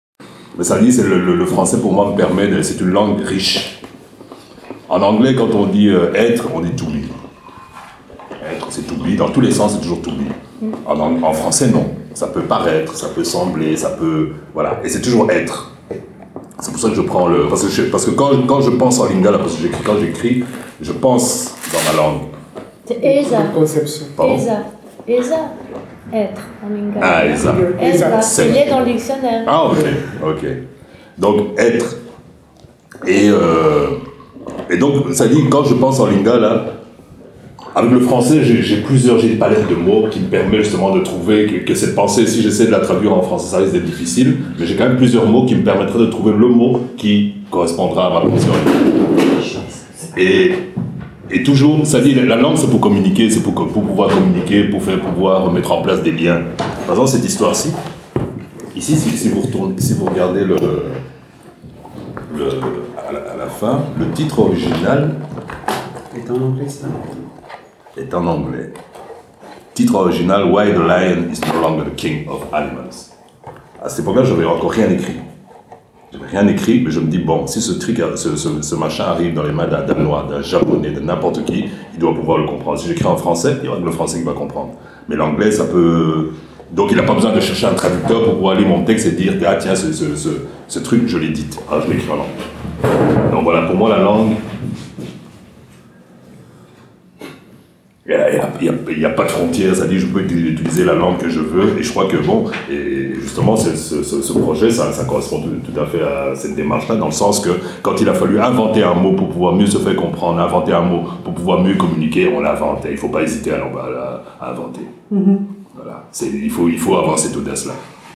Vernissage de l’exposition des collectifs Eza Possibles et OKUP